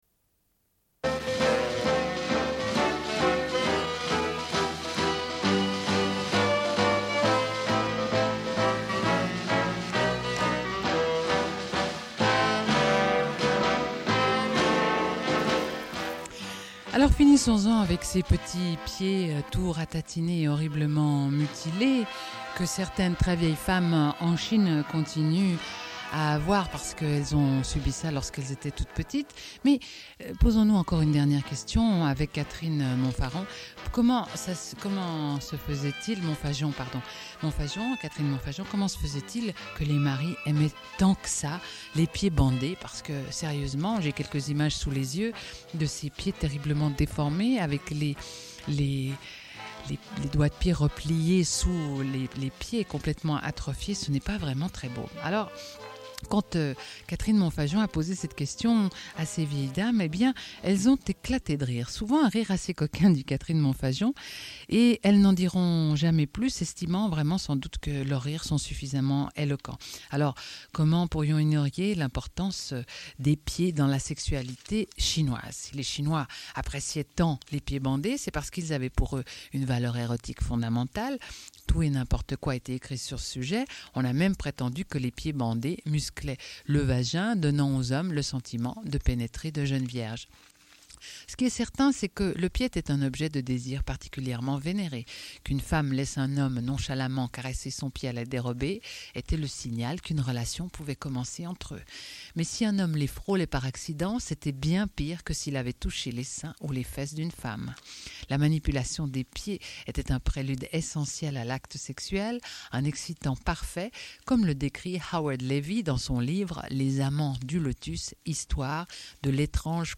Une cassette audio, face A